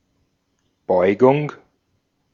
Ääntäminen
IPA : /ɪnˈflɛkʃən/